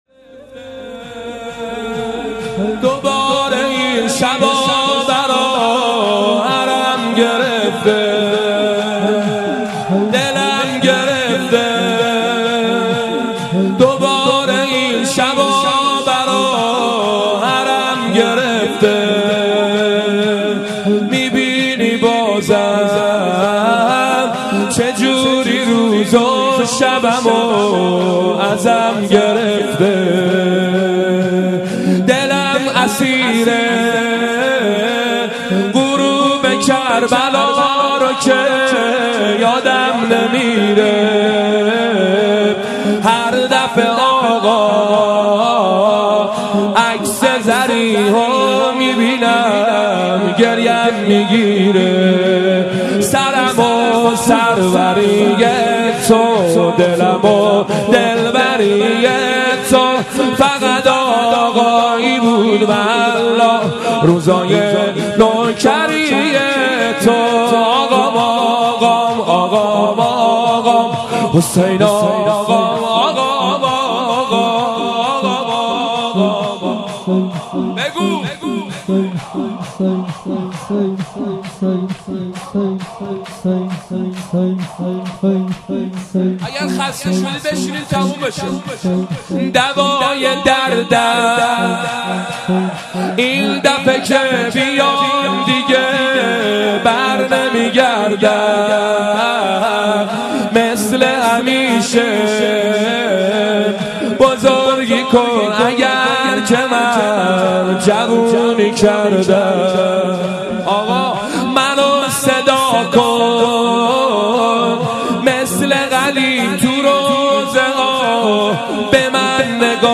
شب هفتم رمضان95، حاج محمدرضا طاهری